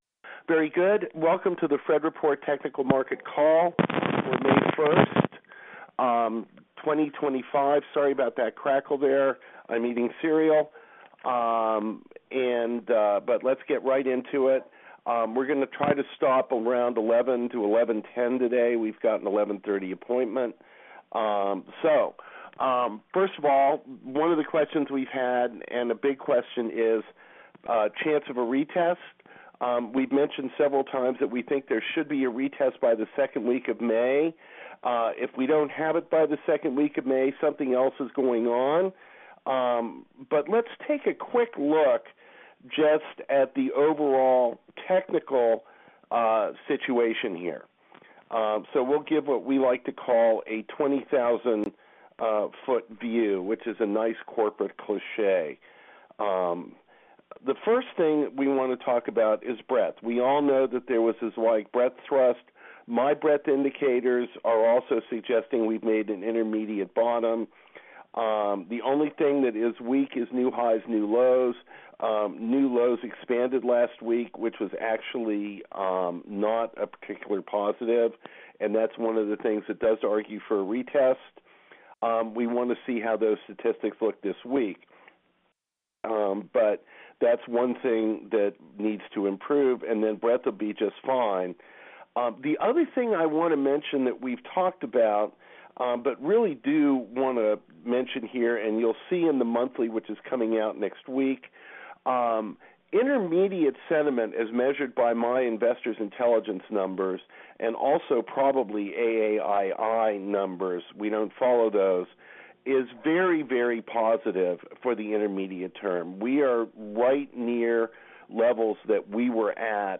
The Fred Report - Conference Call May 1, 2025
Conference Call Recording